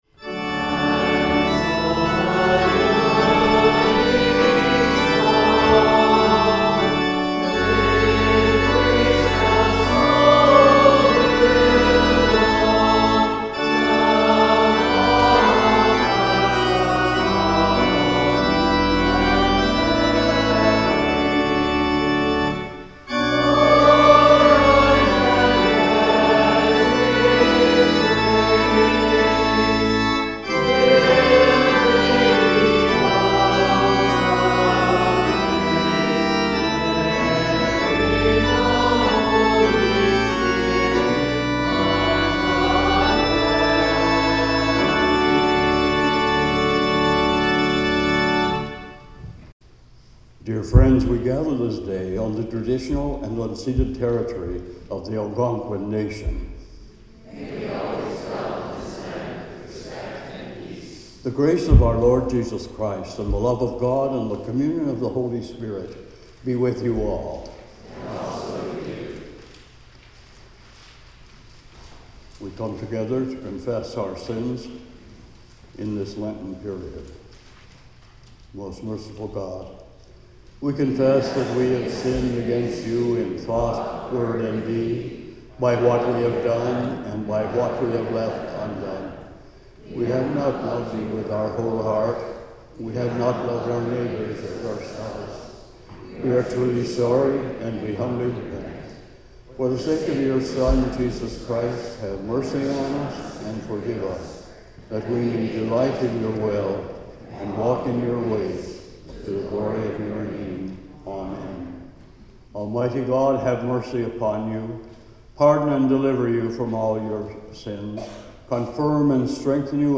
THIRD SUNDAY IN LENT Hymn 645: Come Down O Love Divine (final verse) Greeting, Land Acknowledgment, Confession, Kyrie & Collect of the Day First Reading: Exodus 17:1-7 (reading in Arabic) Psalm 95:1-7 – If today you hear God’s voice, harden not your hearts Second Reading: Romans...